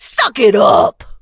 evil_pam_kill_vo_01.ogg